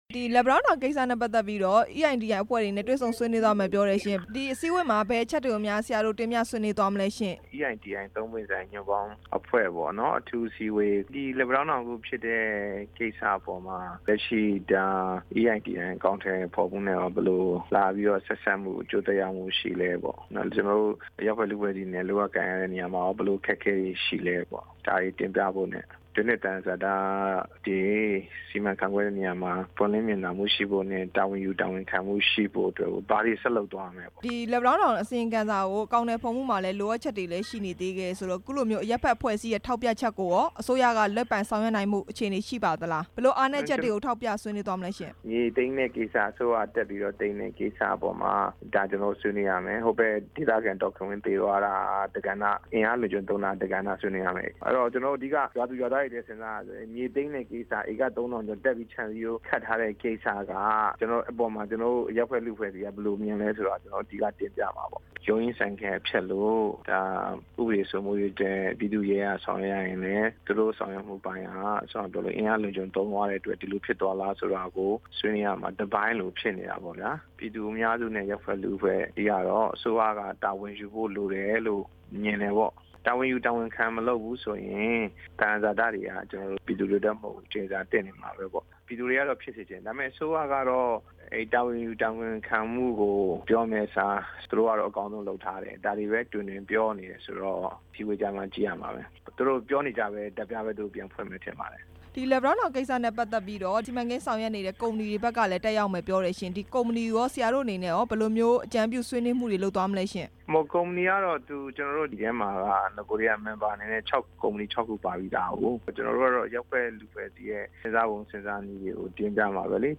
EITI အဖွဲ့ဝင်တွေနဲ့ ဝမ်ပေါင် တာဝန်ရှိသူတွေ တွေ့မယ့်အကြောင်း မေးမြန်းချက်